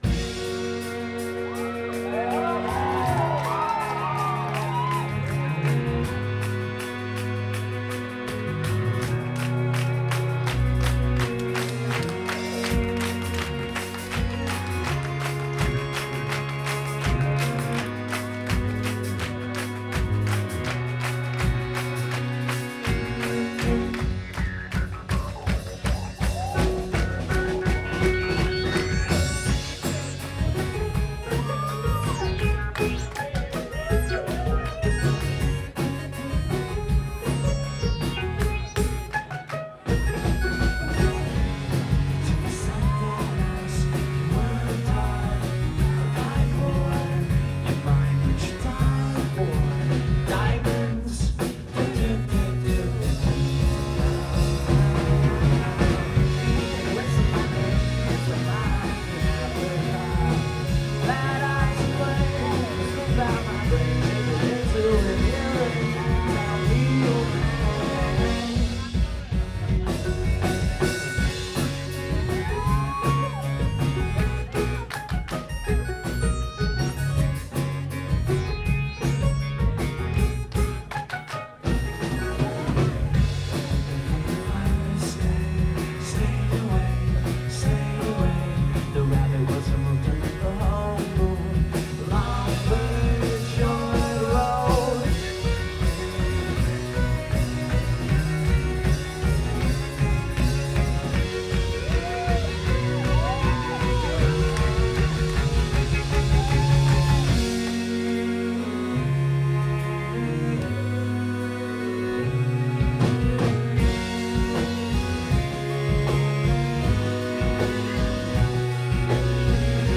2006-11-06 Crocodile Cafe – Seattle, WA